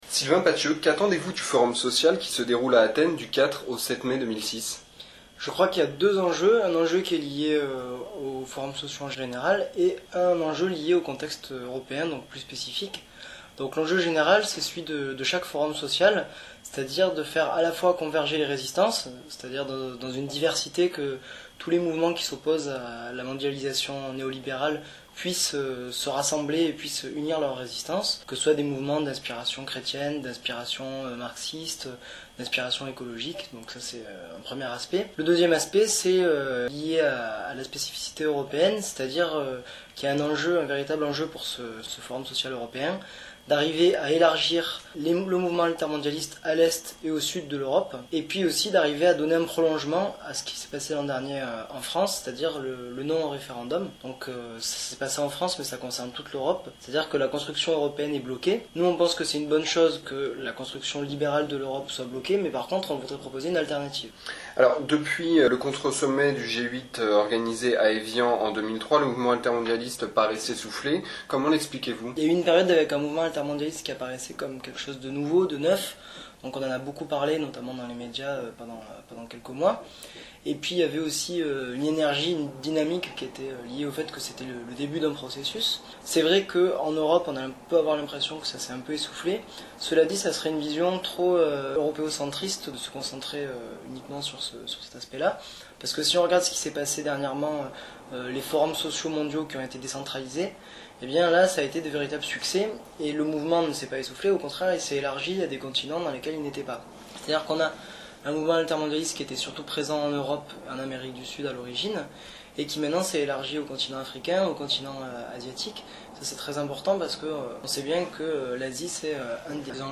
L’entretien audio (au format mp3)